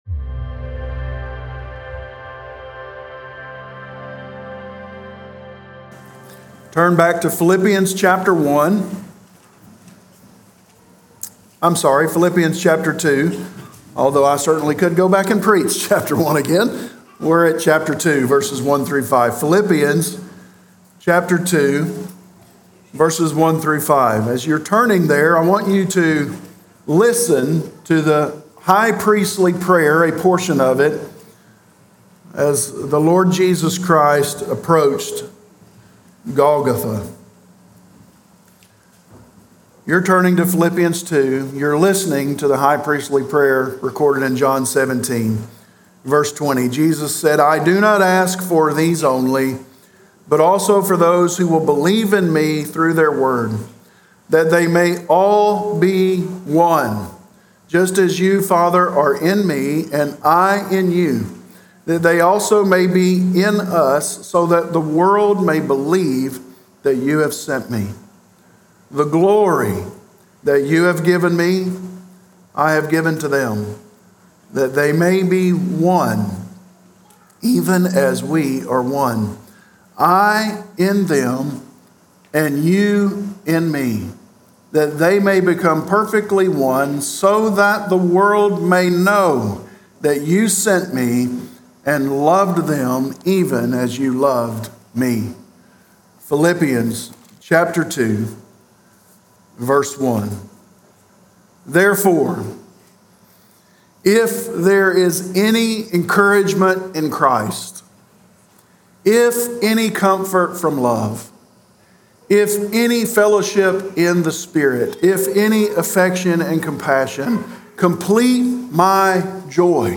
Corydon Baptist Church - A Christian Family of Disciple Making Disciples